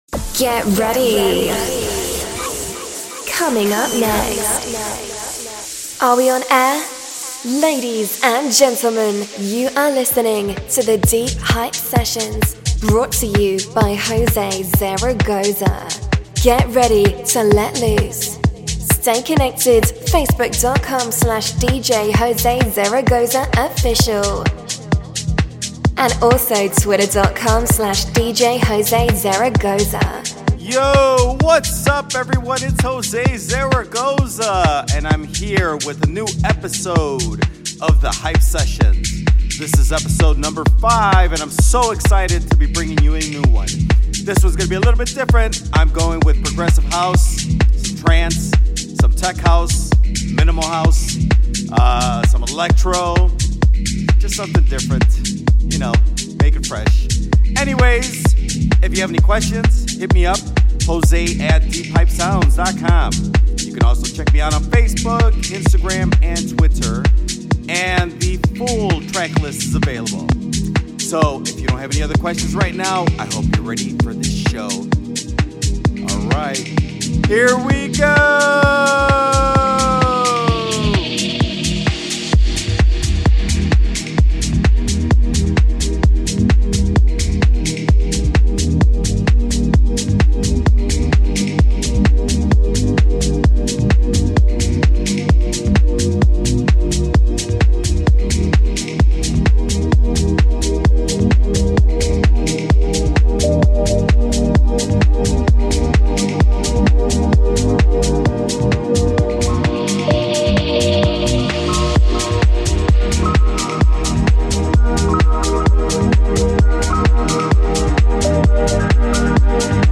Progressive House and more!